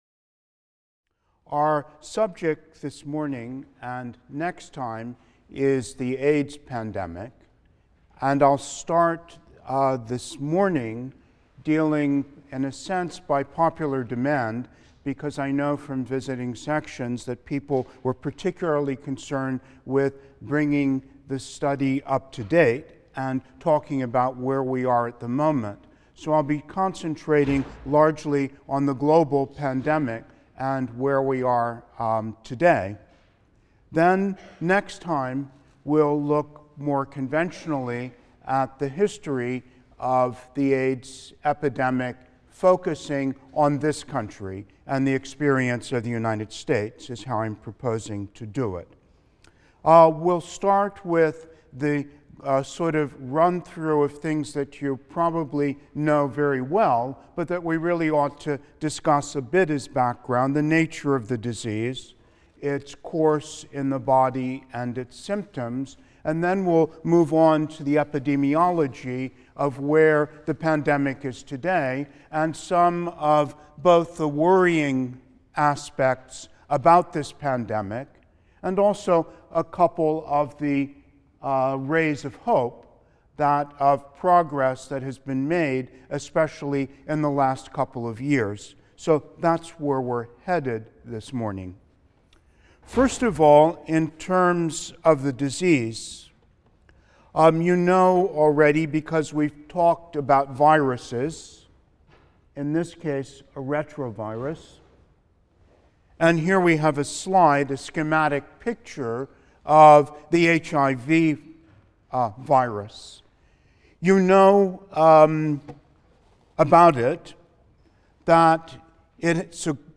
HIST 234 - Lecture 22 - AIDS (I) | Open Yale Courses